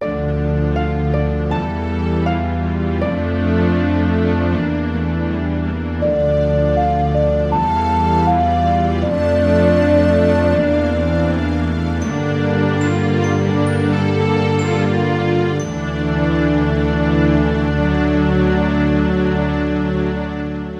Kategorien: Klassische